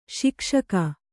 ♪ śikṣaka